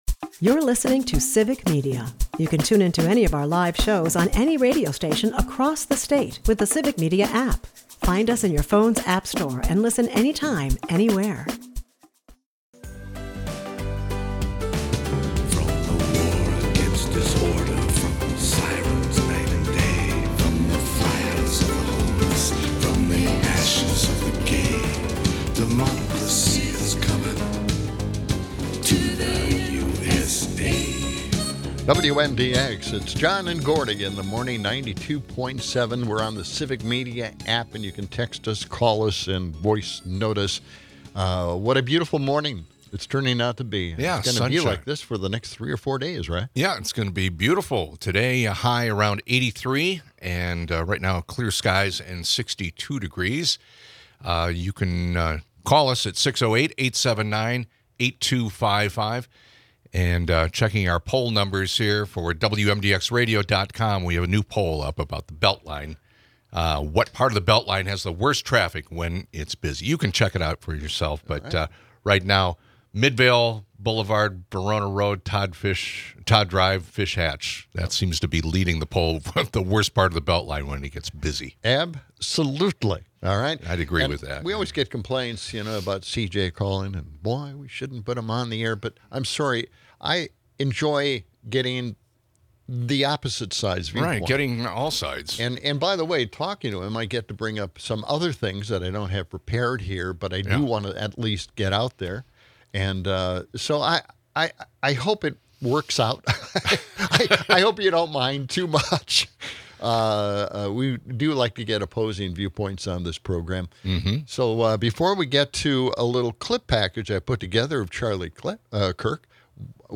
They confront the dangerous normalization of hateful speech and its implications on societal values. Former Madison Mayor, Paul Soglin, weighs in on the importance of understanding true fascism and the necessity of long-term organizing to combat right-wing strategies. The episode touches on civic responsibilities, the power of words, and the role of media in shaping narratives.